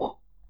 high_jump.wav